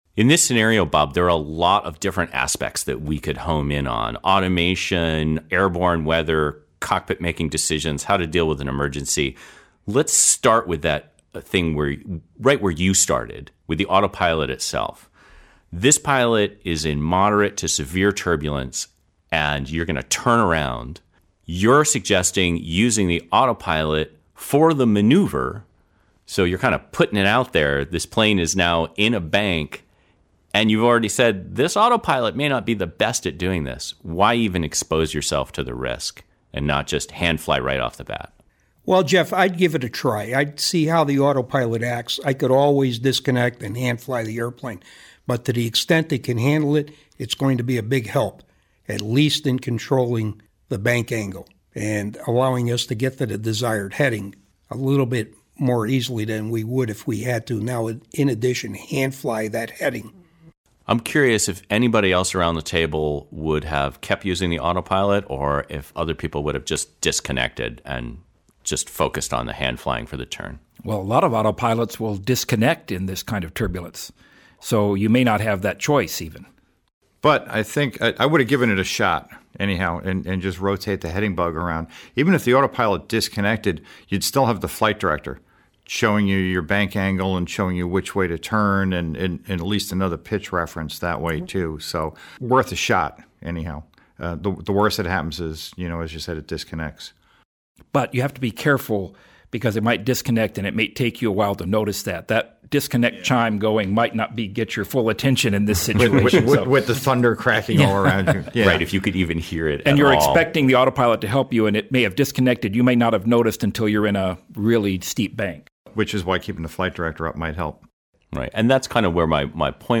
Sucked_into_a_thunderstorm_roundtable.mp3